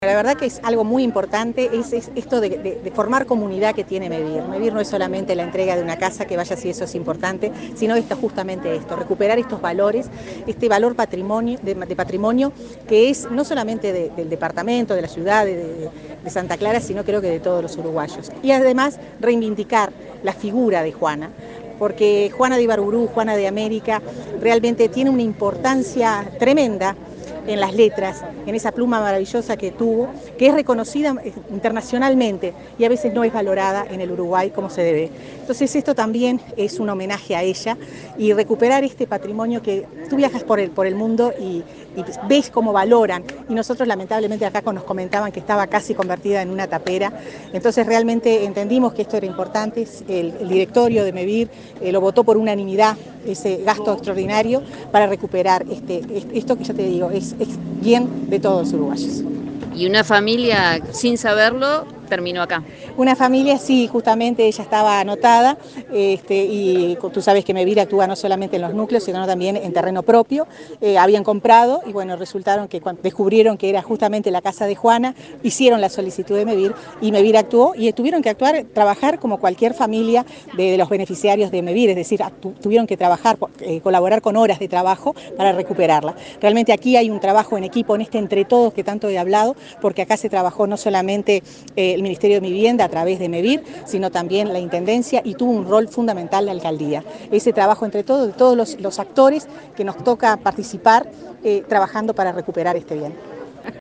Entrevista a la ministra de Vivienda, Irene Moreira